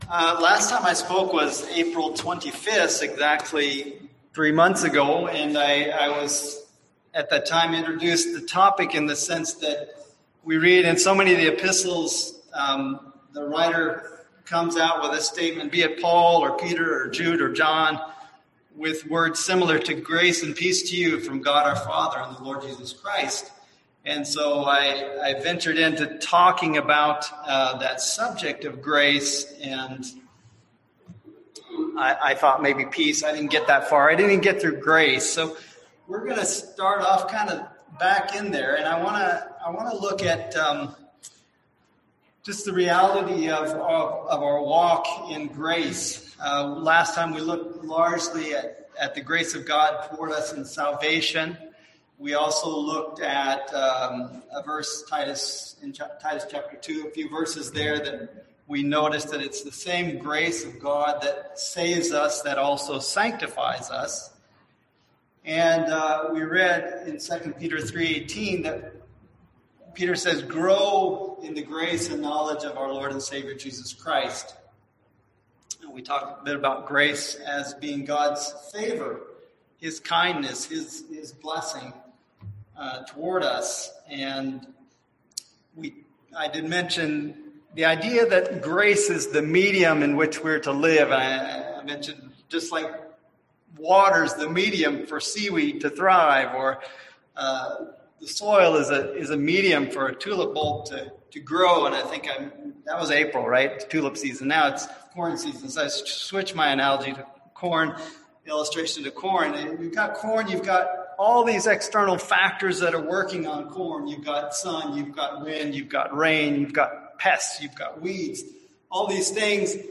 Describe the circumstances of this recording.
Service Type: Sunday AM Topics: Forgiveness , Grace , Love our Neighbors